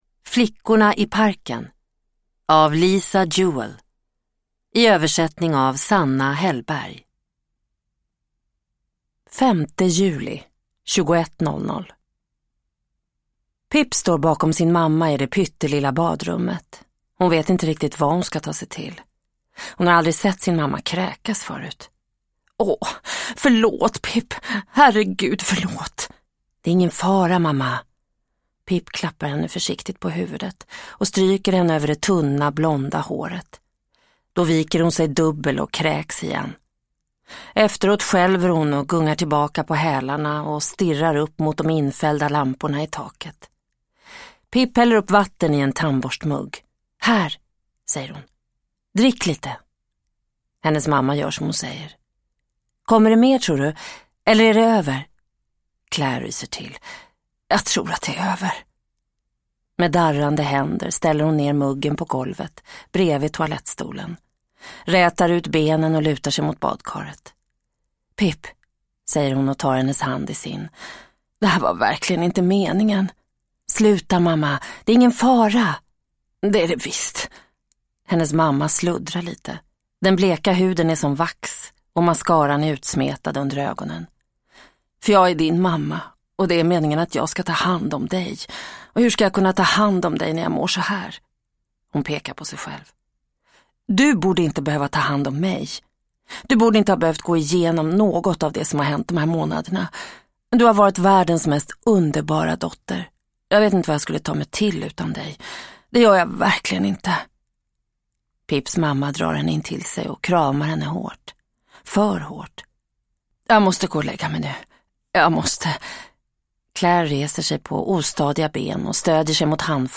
Flickorna i parken – Ljudbok – Laddas ner